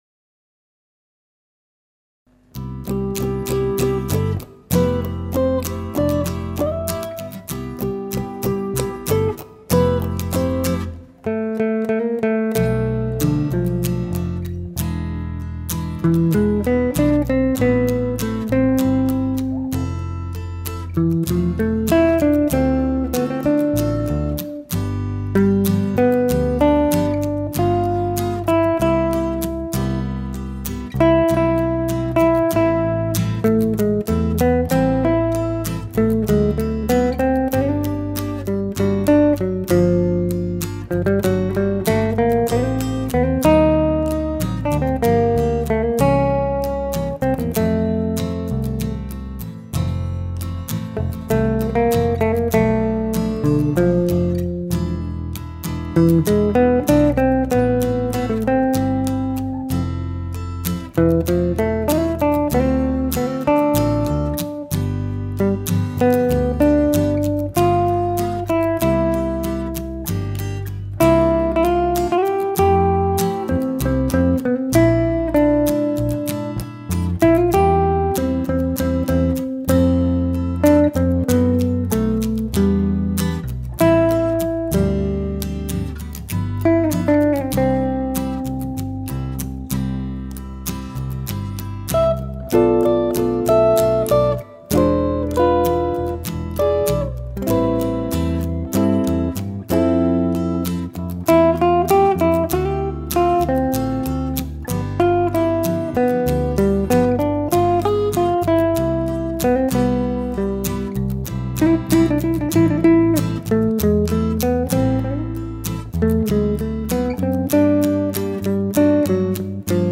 Chitarre varie, basso